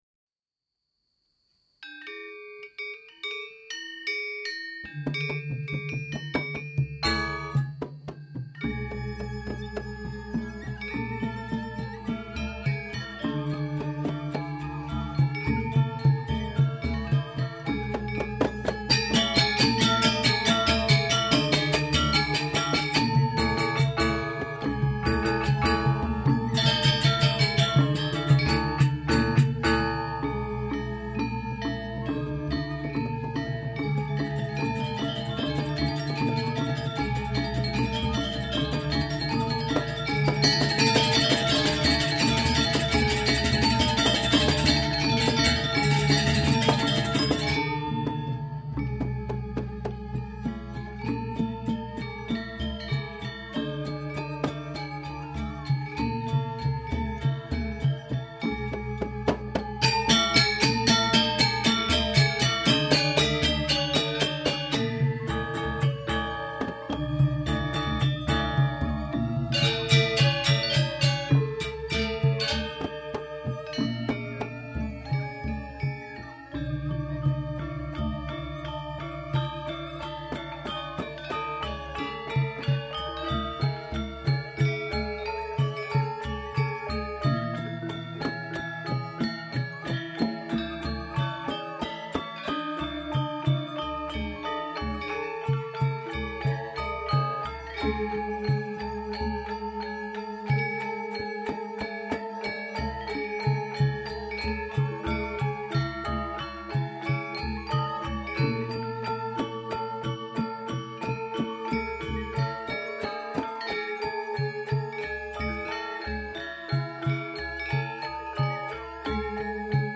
Gamelan Gong Kebyar - Gabor